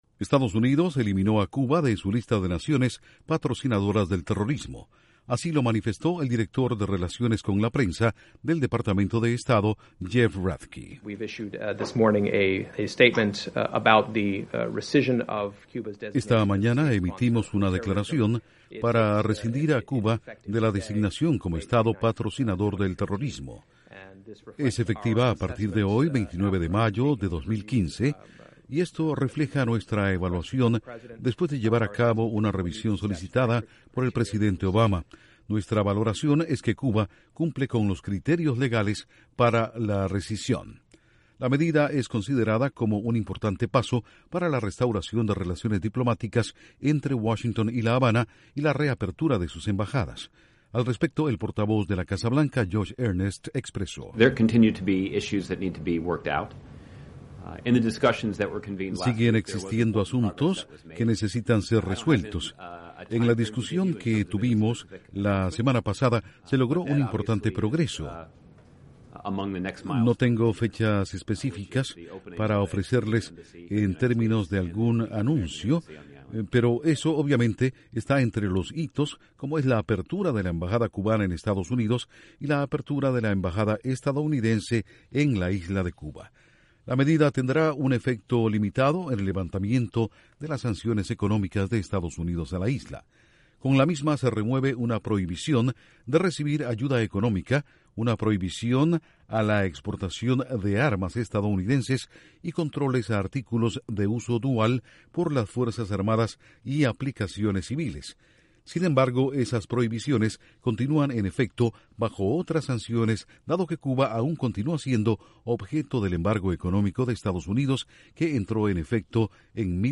Estados Unidos saca a Cuba de la lista de naciones patrocinadoras del terrorismo. Voceros de la Casa Blanca y el Departamento de Estado explican.